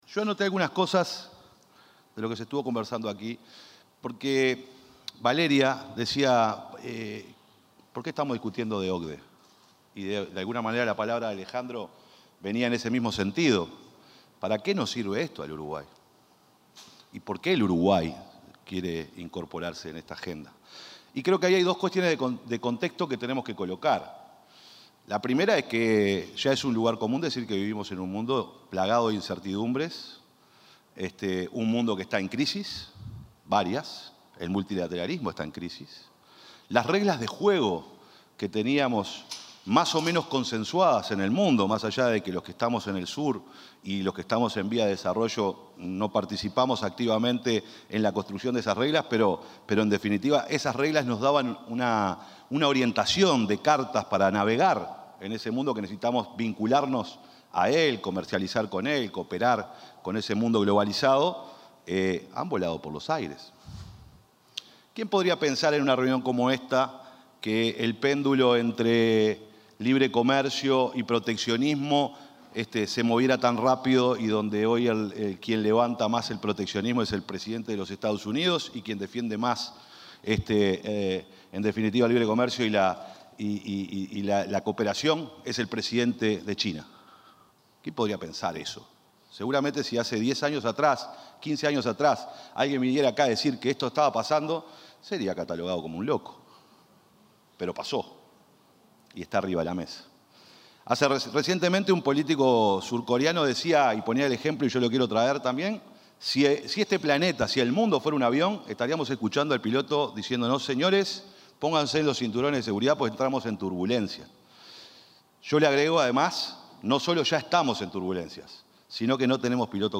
Palabras del secretario de la Presidencia, Alejandro Sánchez
Palabras del secretario de la Presidencia, Alejandro Sánchez 15/08/2025 Compartir Facebook X Copiar enlace WhatsApp LinkedIn El encargado de culminar el encuentro denominado Uruguay y la Organización para la Cooperación y el Desarrollo Económicos: Diálogo Estratégico sobre Inserción Internacional y Reformas fue el secretario de la Presidencia, Alejandro Sánchez.